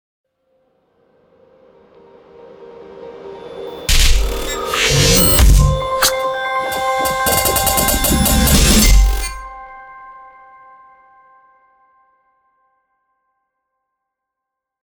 Thể loại: Nhạc nền video